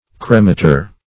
Search Result for " cremator" : The Collaborative International Dictionary of English v.0.48: Cremator \Cre*ma"tor\ (-t?r), n. [L.] One who, or that which, cremates or consumes to ashes.